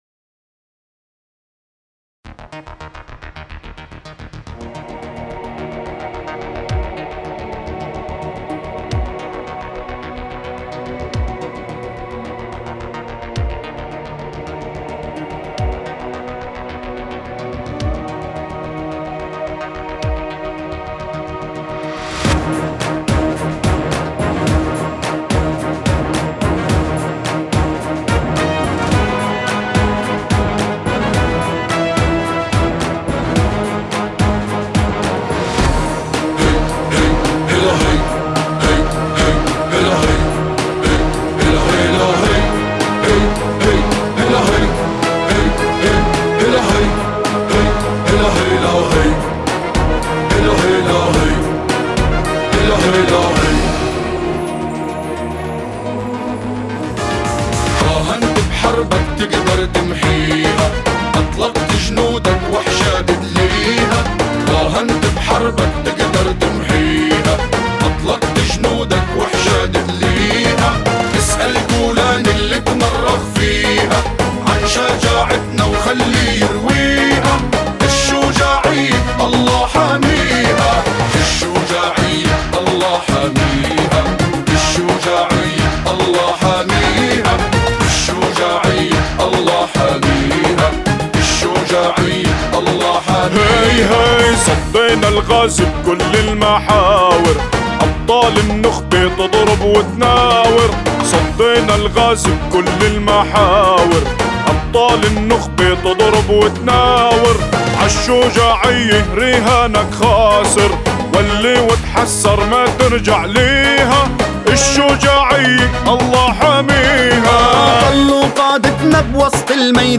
أناشيد فلسطينية... الشجاعة الله حاميها